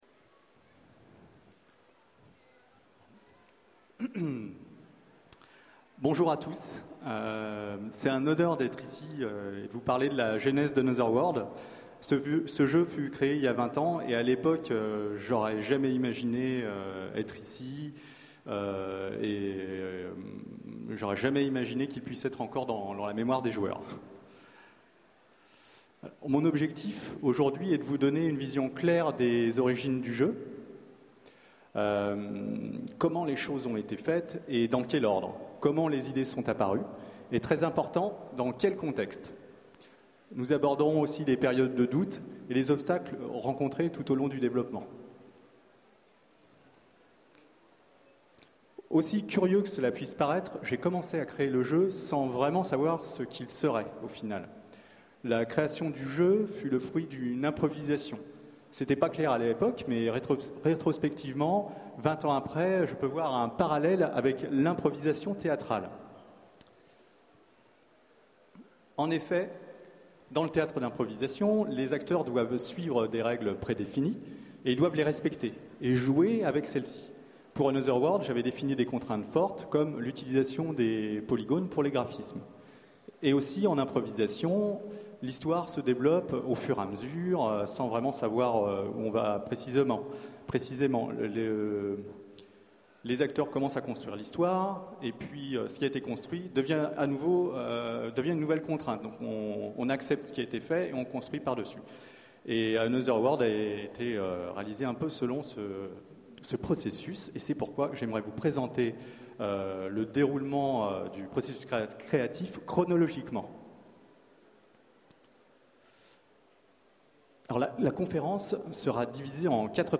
Utopiales 2011 : Conférence Les 20 ans d'Another World